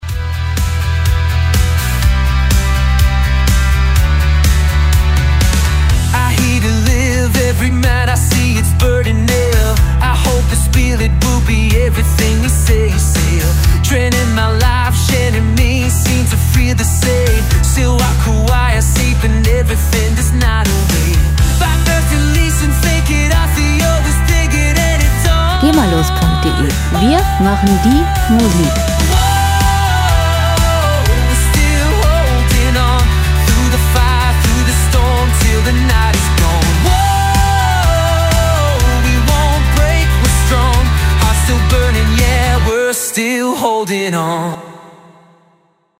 Rockmusik - Legenden
Musikstil: Pop-Rock
Tempo: 124 bpm
Tonart: E-Moll
Charakter: aktiv, munter